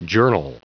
Prononciation du mot journal en anglais (fichier audio)